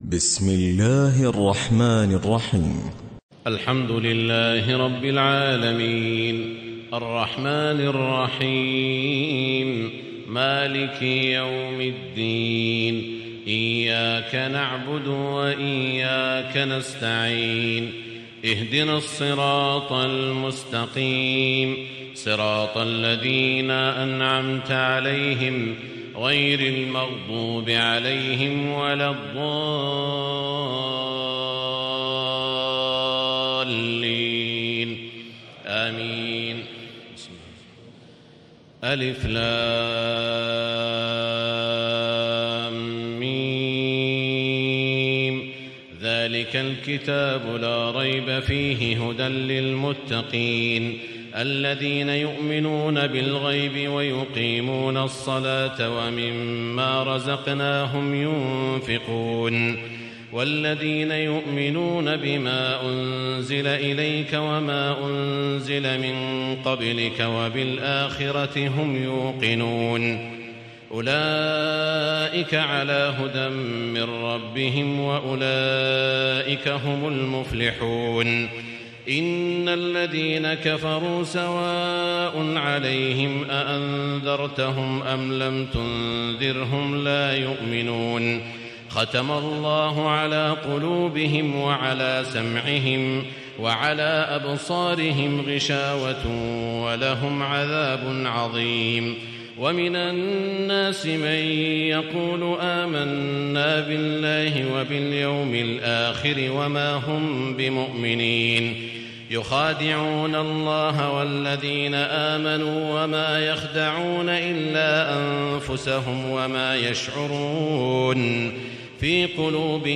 تراويح الليلة الأولى رمضان 1437هـ من سورة البقرة (1-86) Taraweeh 1st night Ramadan 1437 H from Surah Al-Baqara > تراويح الحرم المكي عام 1437 🕋 > التراويح - تلاوات الحرمين